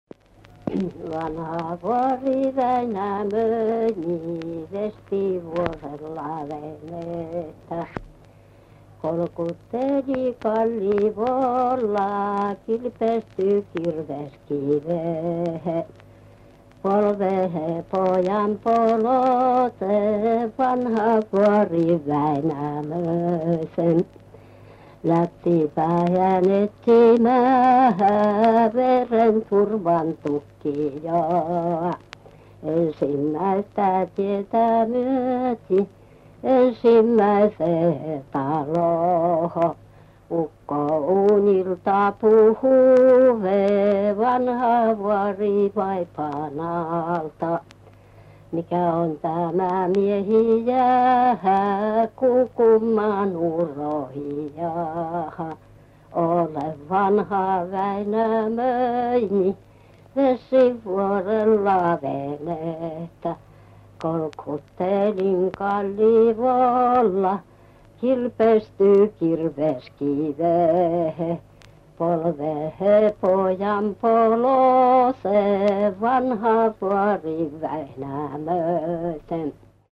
7. Kuuntele ja tulkitse runolaulua.